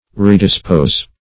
Redispose \Re`dis*pose"\ (-p?z"), v. t.